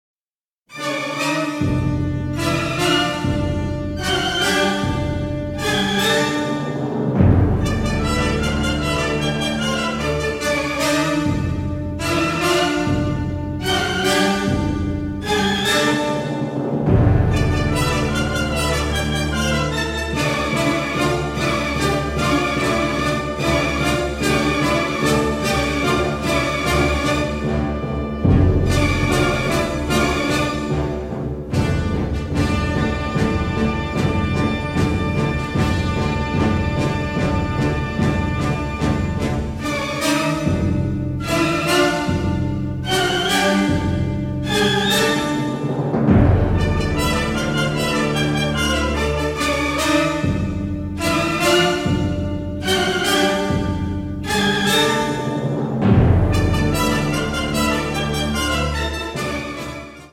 ALBUM STEREO TRACKS